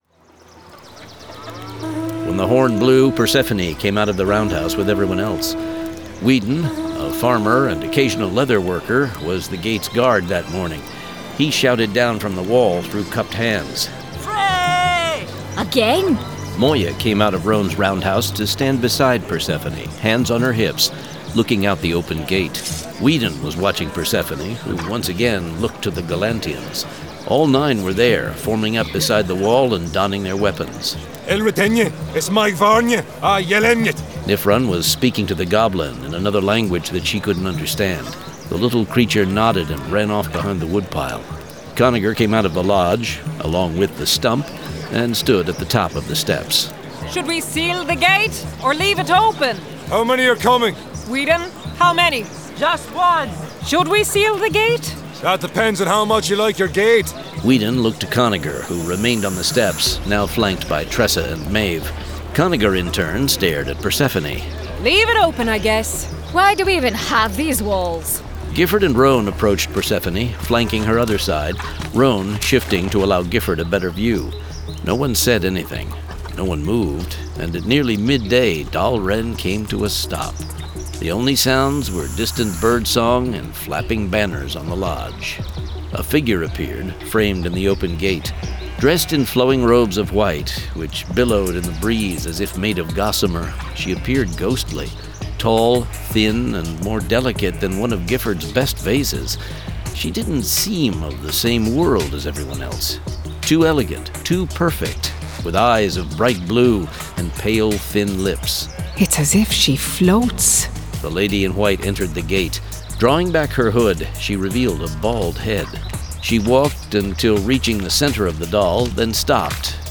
The Legends of the First Empire 1: Age of Myth 2 of 2 [Dramatized Adaptation]